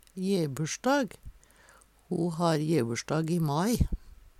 jebursjdag - Numedalsmål (en-US)